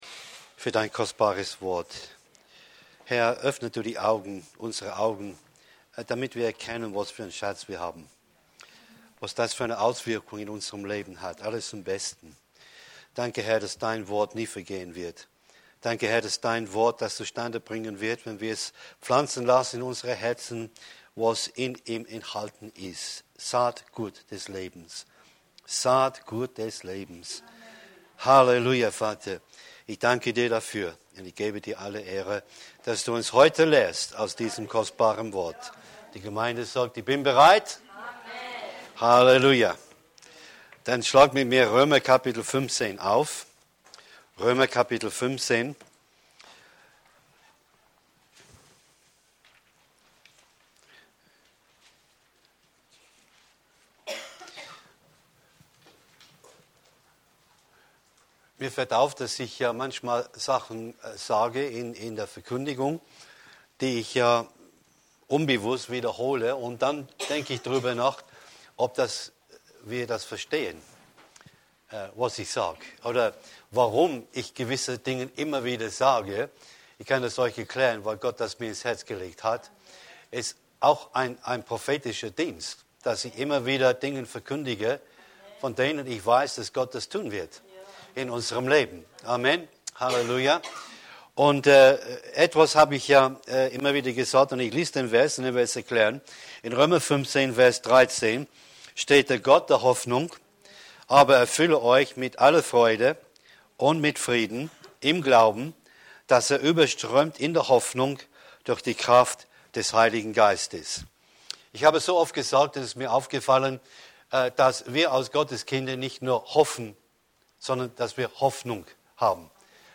Römer 5:5 Predigt herunterladen